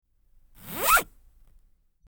Zipper
Zipper.mp3